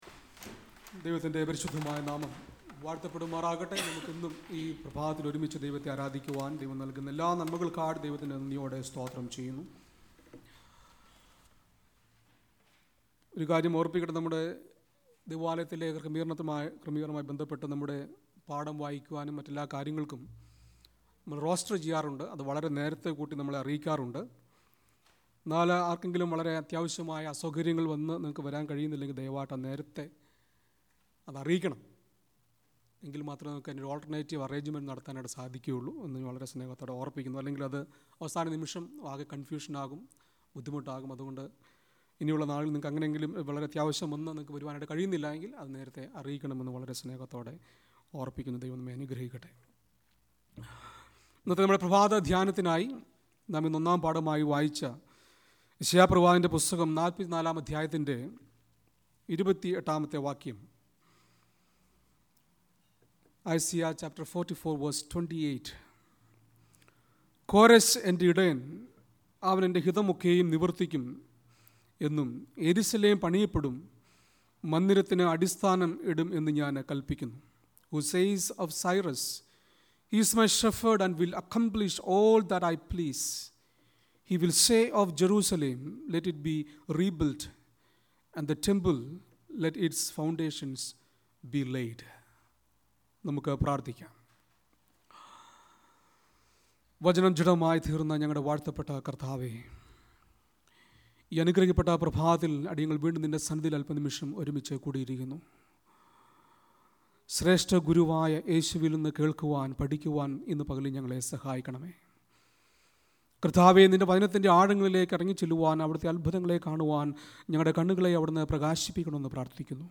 Weekly Sermon Podcast